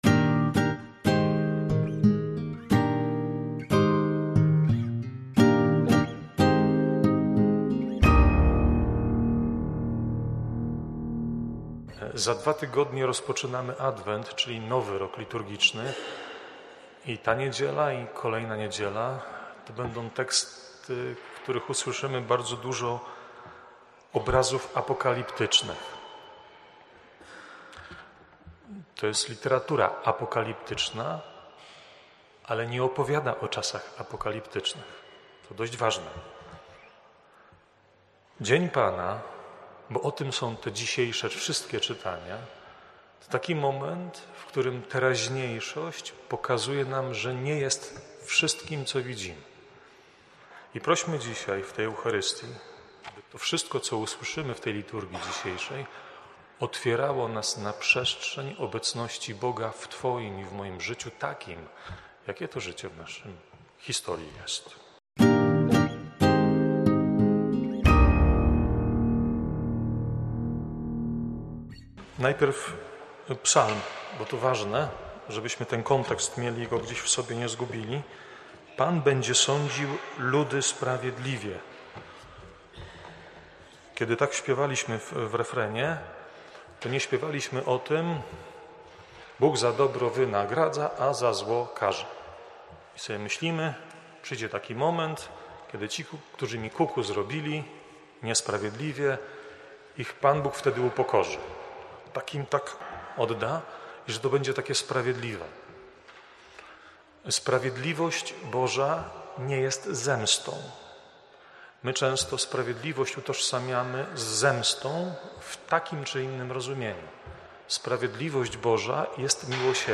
kazania.
wprowadzenie do Liturgii, oraz homilia: